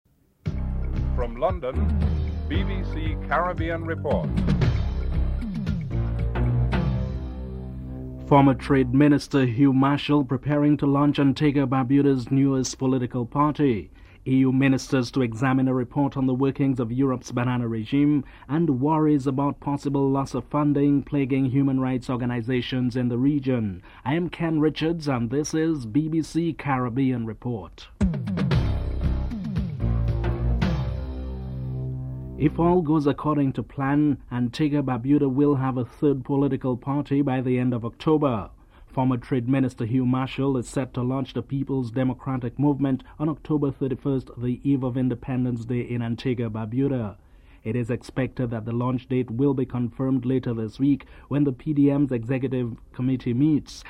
In this report Antigua and Barbuda's former Trade Minister Hugh Marshall prepares to launch a new political party. Mr. Marshall comments on why he has decided to form a new party.
8. Recap of top stories (14:28-15:20)